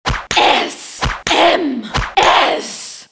/128kbps) 16kbps (6.1кб) 32kbps (12.3кб) 48kbps (18.9кб) 96kbps (37.6кб) Описание: S_M_S (Женский ор) ID 24638 Просмотрен 72762 раз Скачан 40148 раз Скопируй ссылку и скачай Fget-ом в течение 1-2 дней!